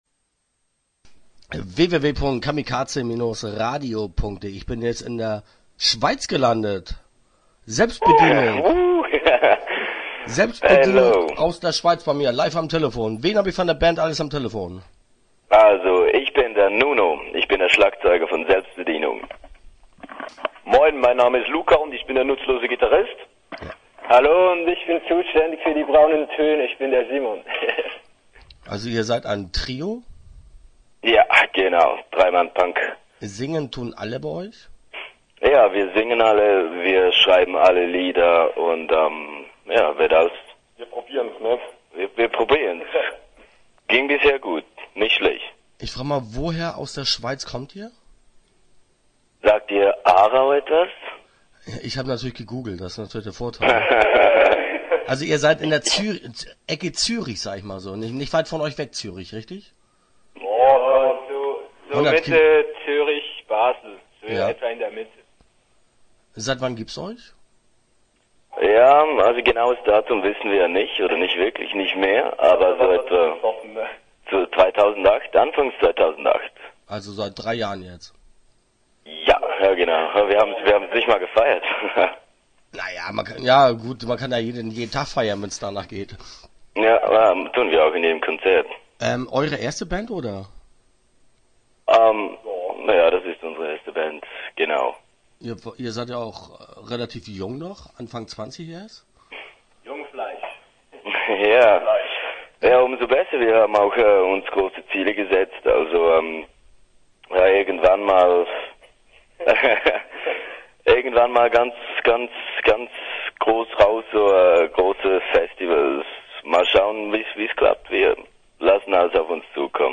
Start » Interviews » Selbstbedienung